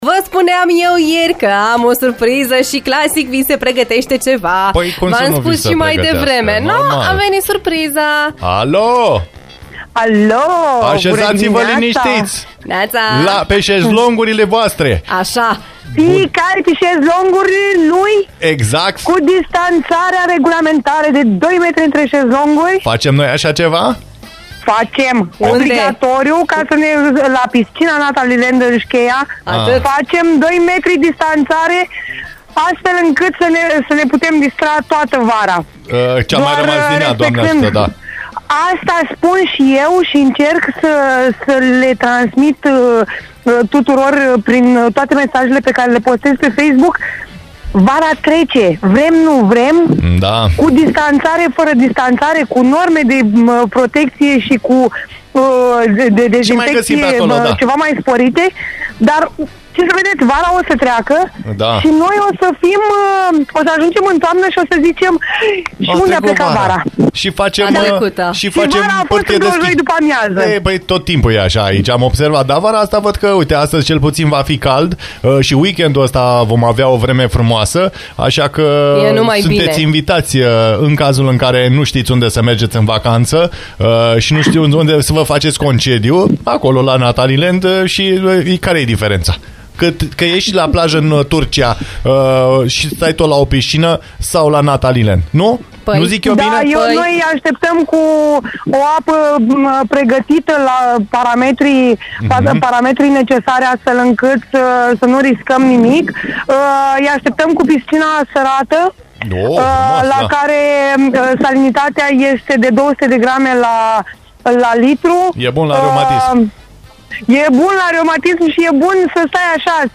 Nu ne-am putut abţine să nu luăm pulsul chiar de la faţa locului.